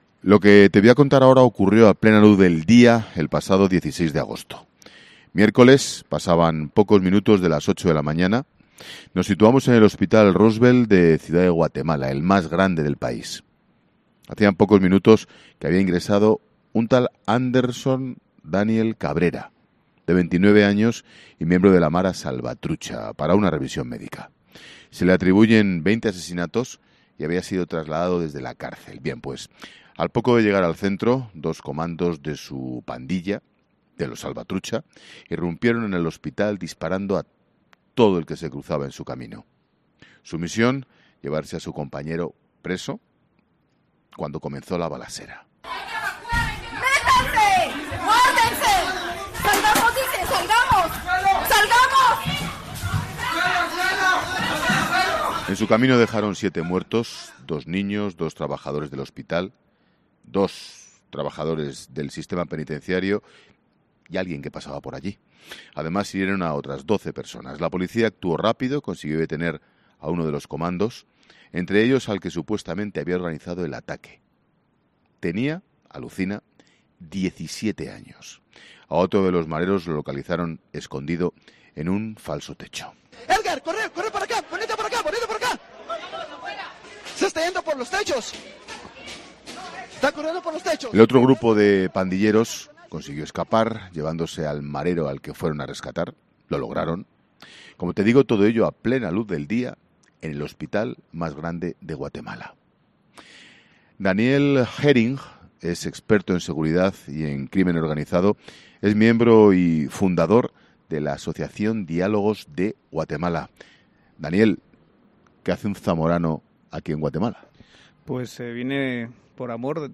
experto en seguridad y en crimen organizado
En el programa especial que está realizando 'La Tarde' de Ángel Expósito desde Guatemala